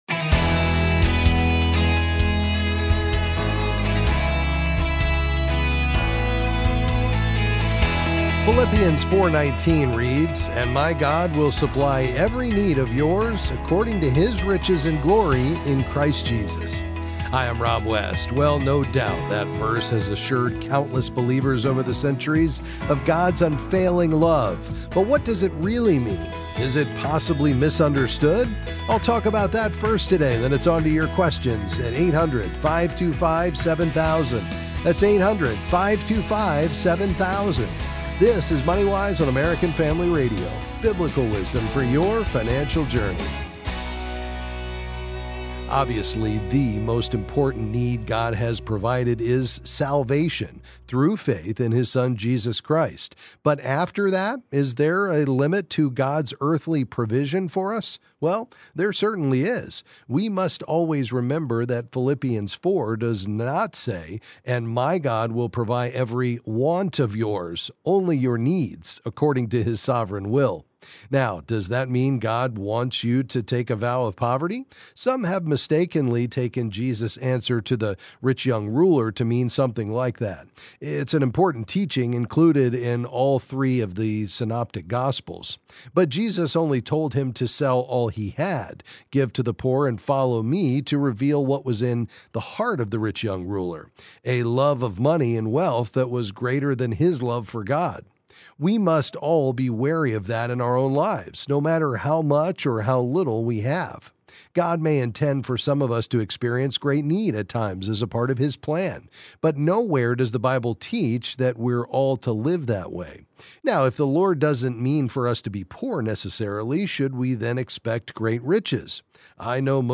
Then he’ll answer your calls and questions on various financial topics.